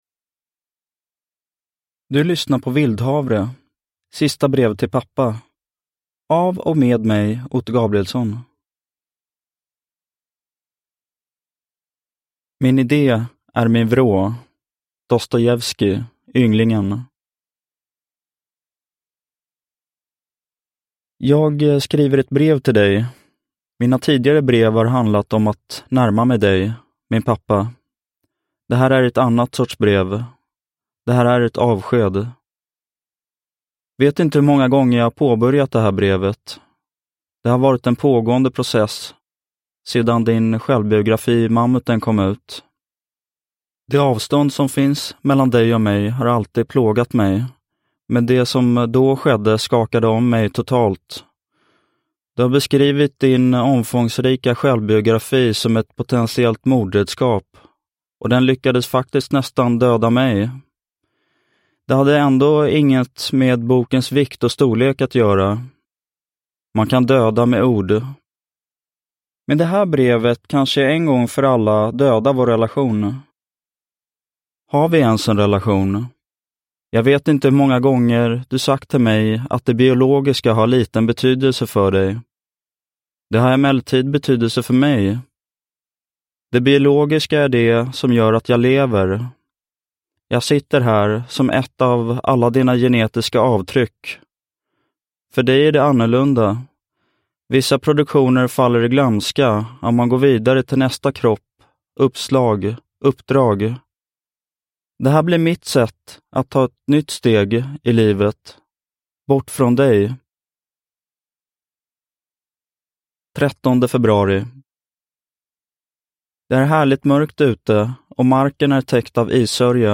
Vildhavre – Ljudbok